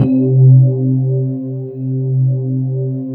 44 SYNTH 1-R.wav